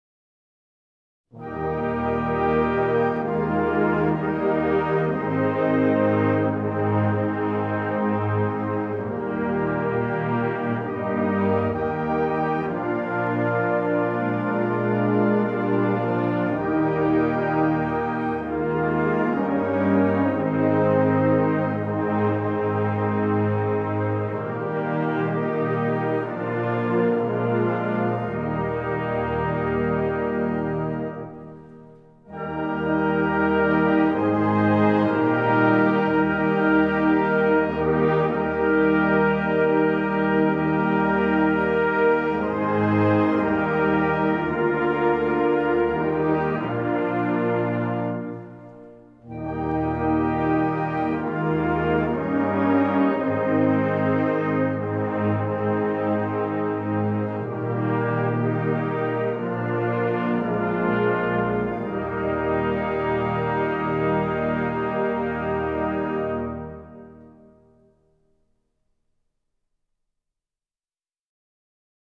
Gattung: Choralsammlung
Besetzung: Blasorchester